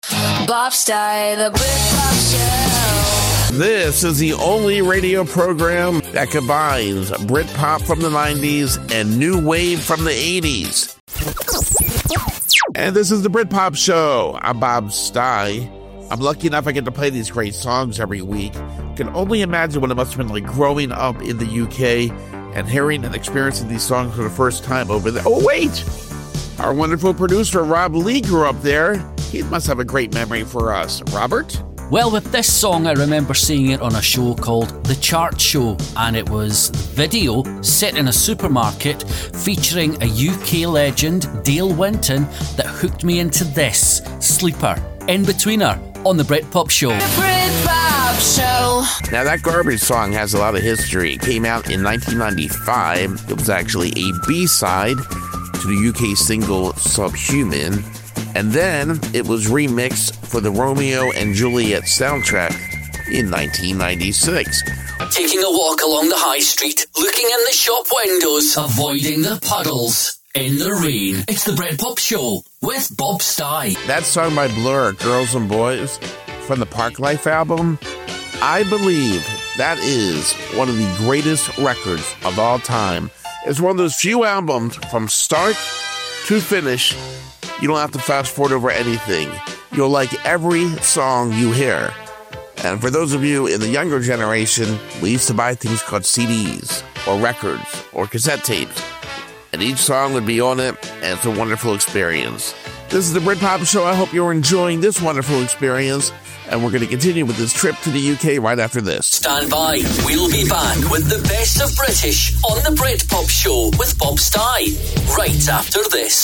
britpopdemo.mp3